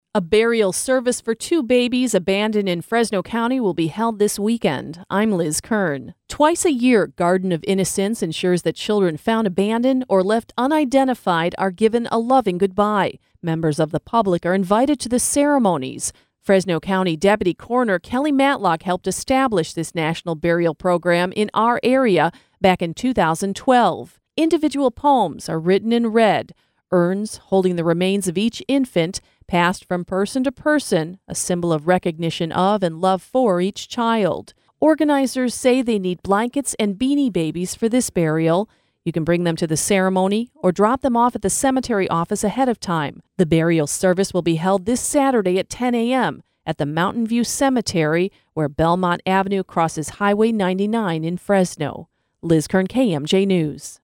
LK-WEB-BURIAL-SERVICE.mp3